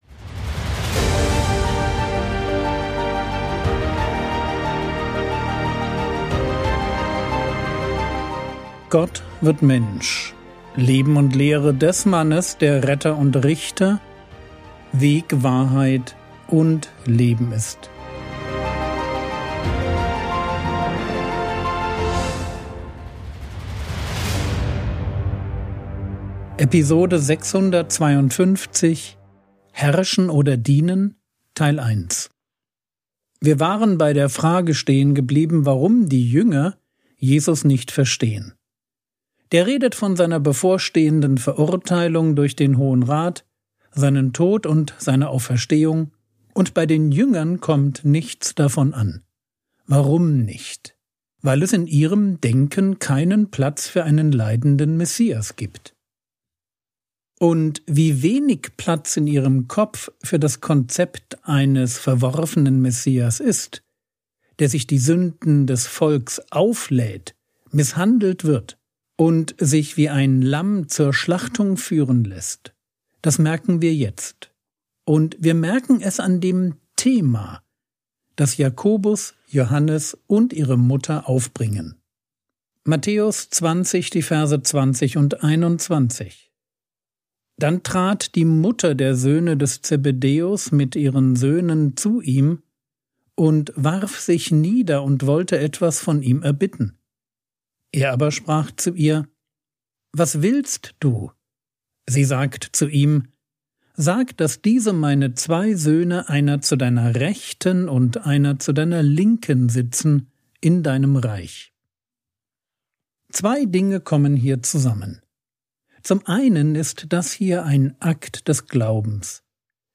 Episode 652 | Jesu Leben und Lehre ~ Frogwords Mini-Predigt Podcast